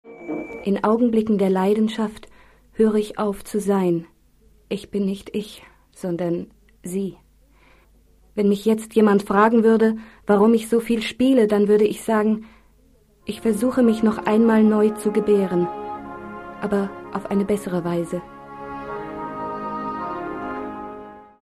Sprecherin deutsch.
Sprechprobe: Sonstiges (Muttersprache):
Female voice over artist German